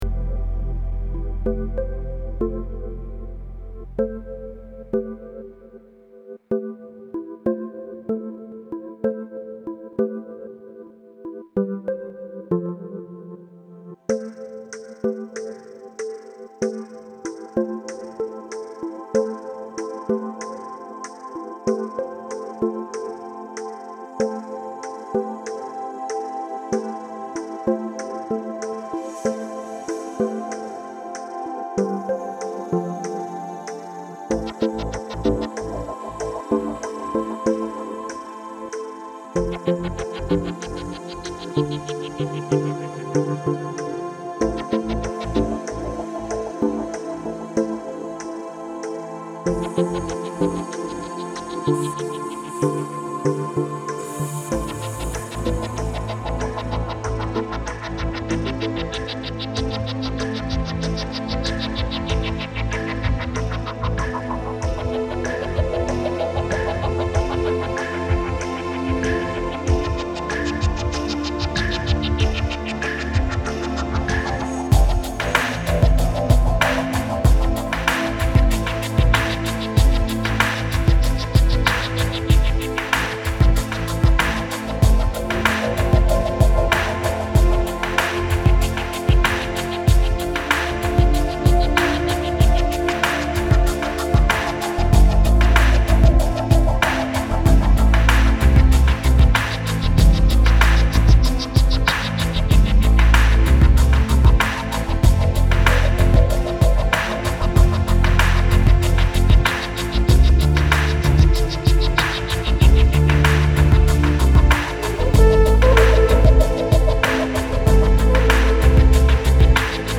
~~ Relaxing Music ~~